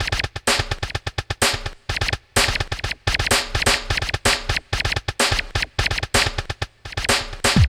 07.1 LOOP1.wav